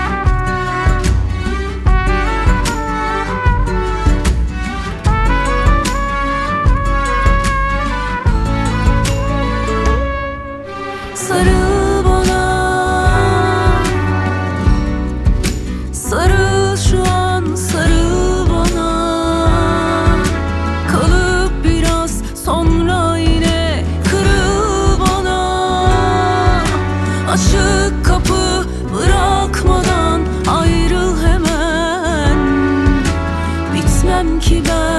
Жанр: Поп / Турецкая поп-музыка